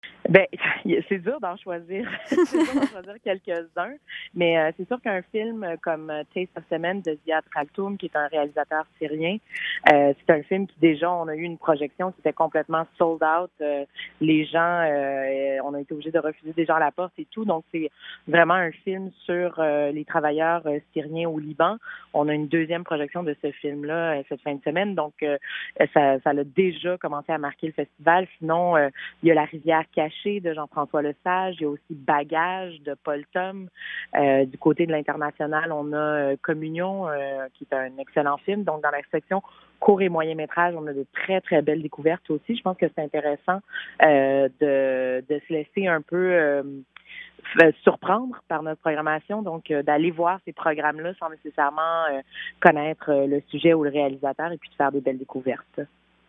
Courte bio de l'interviewée